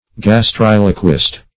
Search Result for " gastriloquist" : The Collaborative International Dictionary of English v.0.48: Gastriloquist \Gas*tril"o*quist\, n. [Gr. gasth`r, gastro`s, stomach + L. loqui to speak.]
gastriloquist.mp3